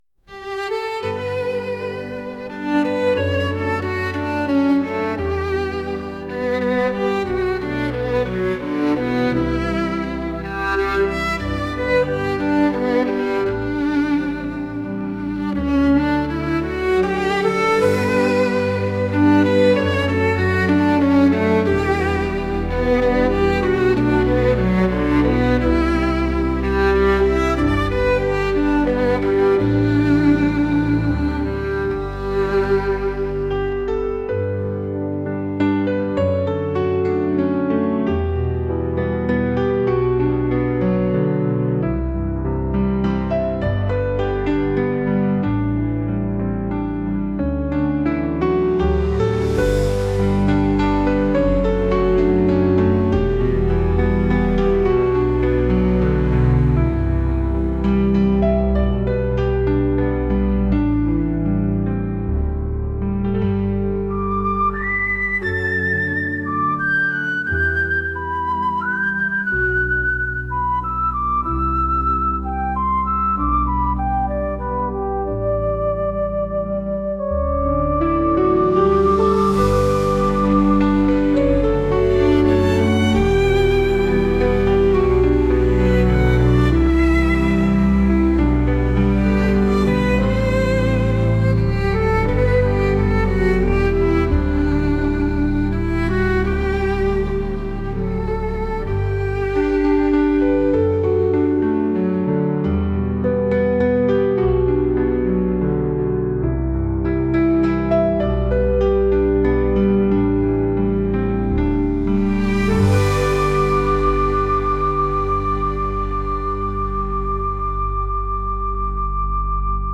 懐かしさを感じるようなノスタルジックな音楽です。